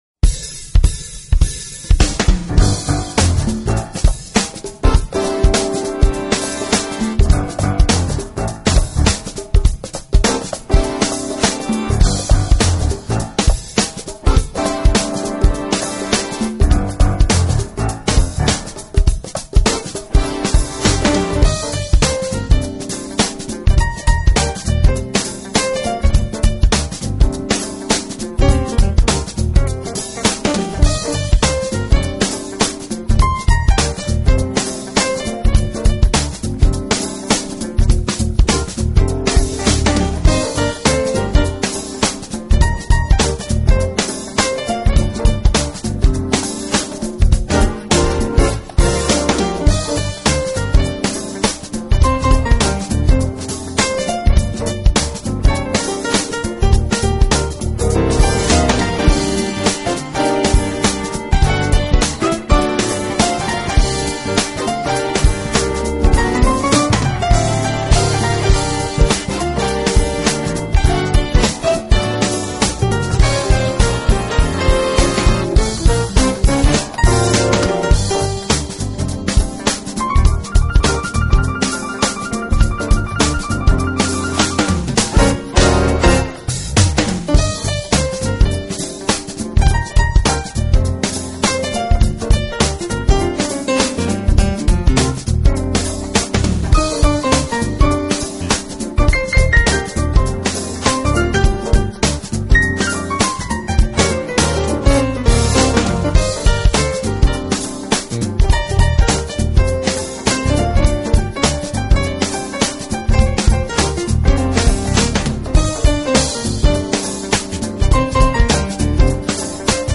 Genre: Smooth Jazz / Piano